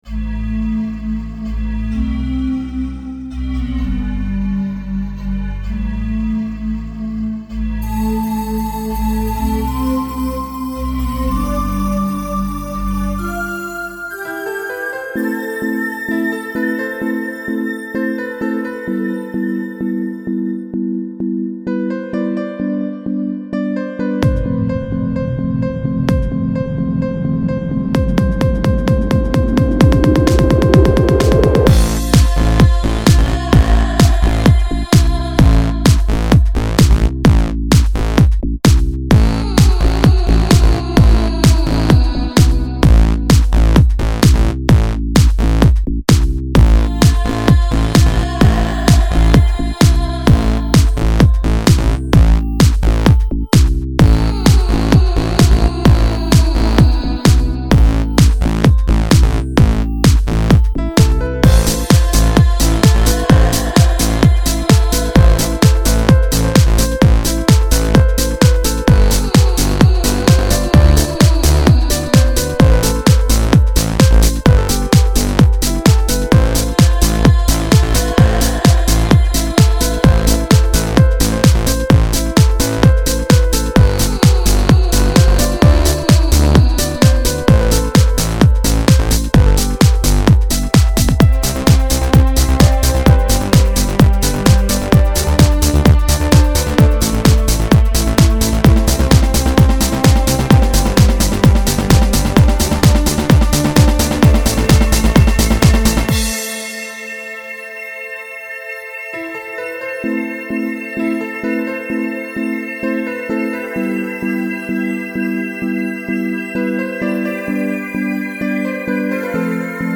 Electro House Remix